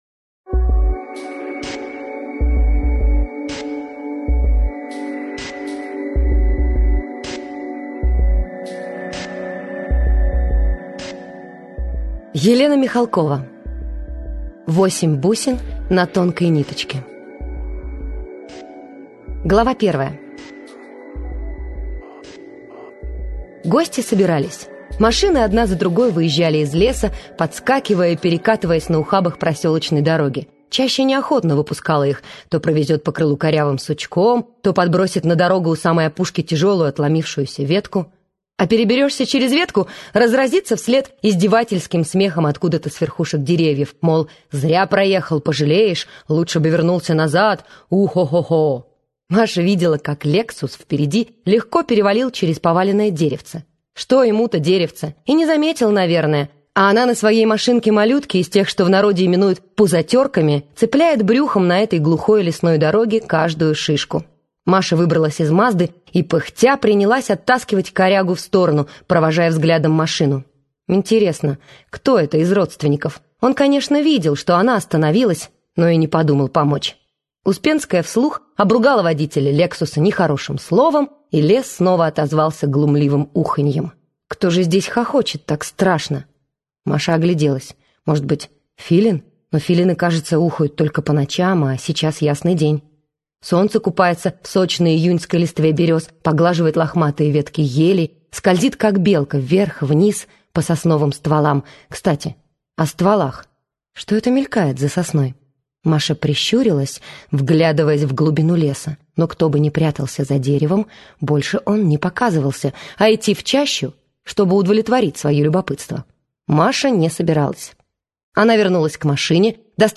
Прослушать фрагмент аудиокниги Восемь бусин на тонкой ниточке Елена Михалкова Произведений: 23 Скачать бесплатно книгу Скачать в MP3 Вы скачиваете фрагмент книги, предоставленный издательством